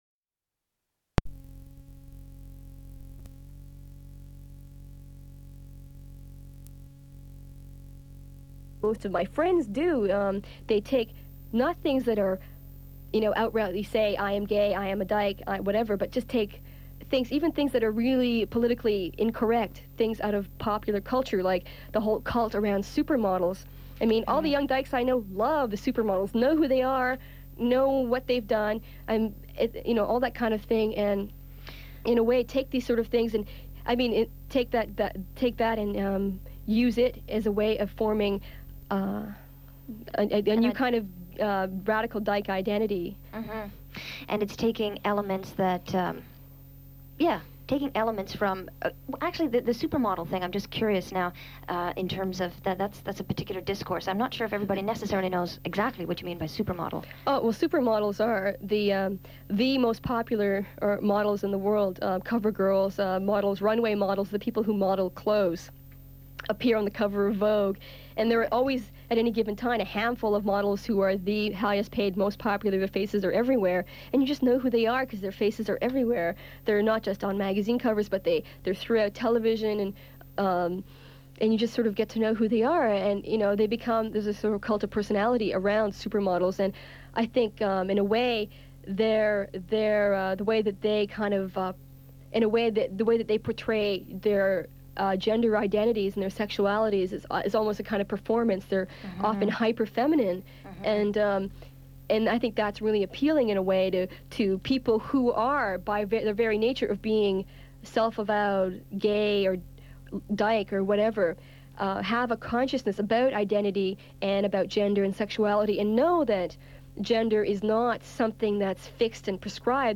They discuss gender and media, lesbian feminism, and lesbians in the media. Recording cuts to interview about the Canadian publishing industry and government subsidy, and may be unrelated content from earlier taping.
The Dykes on Mykes radio show was established in 1987.